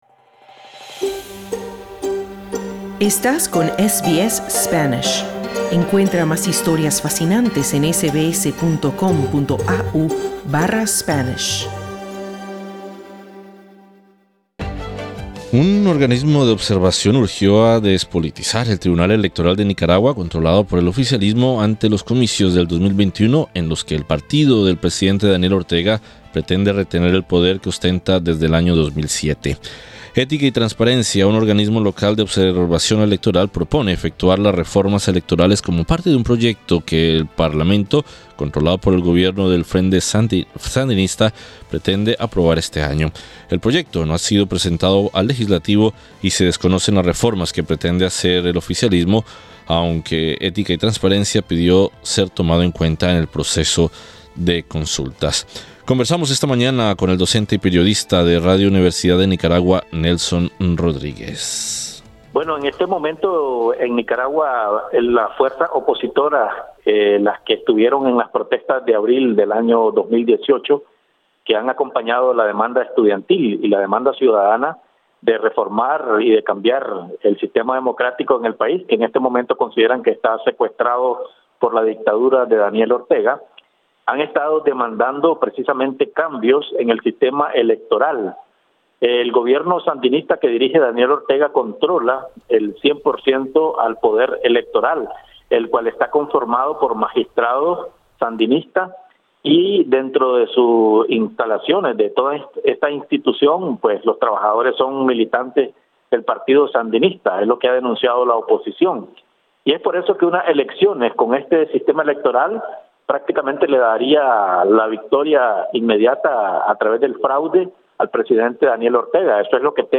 Conversamos con el periodista de Radio Universidad en Nicaragua